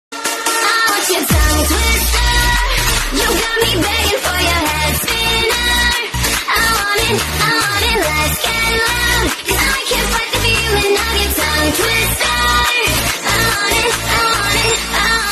Sound hound mi nic nenašel, asi kvůli té zrychlené verzi.
Zpěvačka je soudobá, neměl by být problém najít v jejich hitech i tento.
Bohužel nevím, ale kvalita bídná.